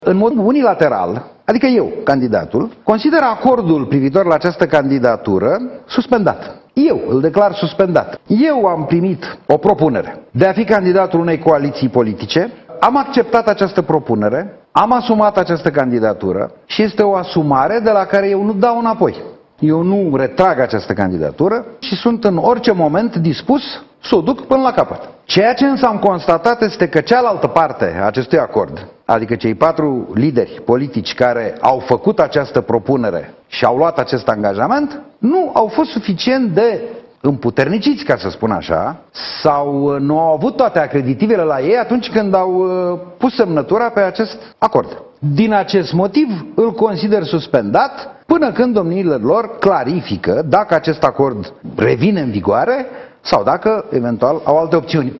Fostul președinte al PNL a spus sâmbătă seara, la Digi 24, că e nemulţumit: în primul rând pentru că nu a fost stabilită încă nicio dată pentru alegeri, iar în al doilea rând pentru că nu a existat un vot unanim din partea Coaliţiei pentru candidatura sa.